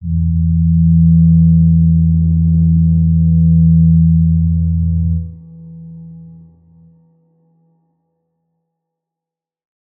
G_Crystal-E3-pp.wav